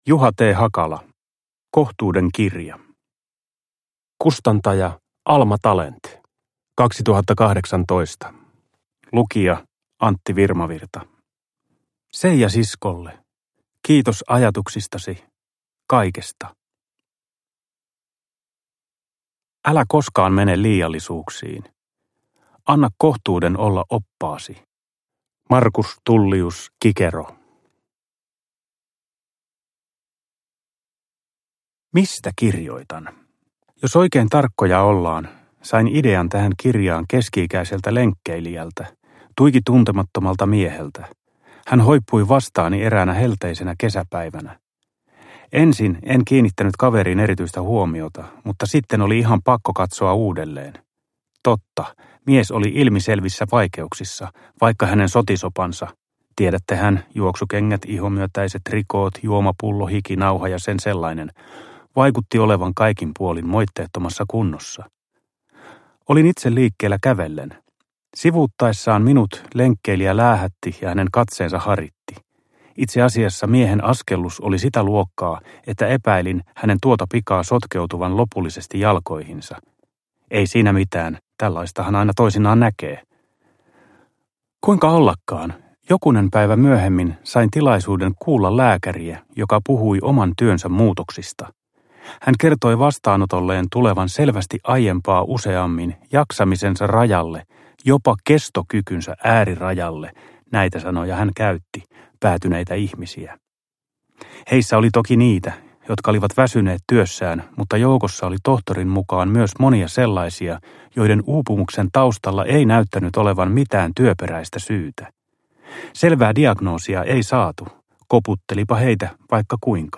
Kohtuuden kirja – Ljudbok – Laddas ner
Uppläsare: Antti Virmavirta